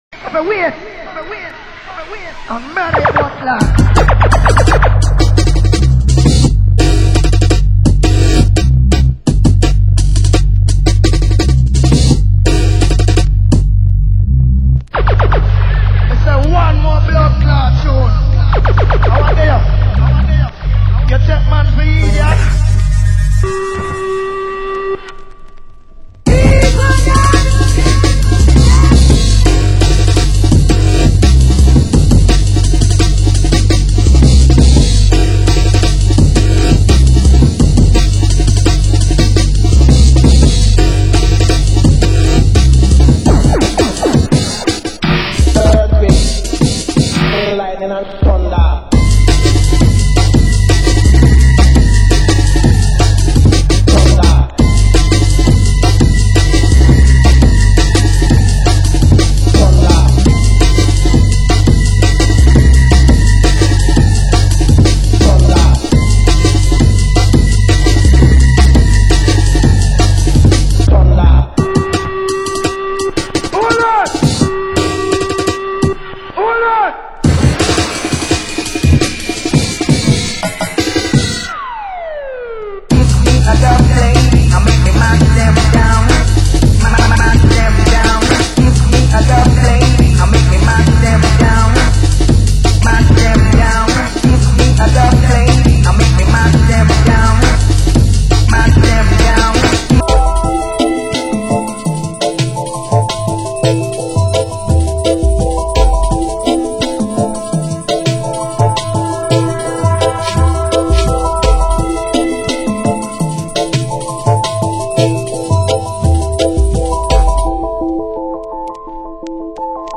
Genre: Jungle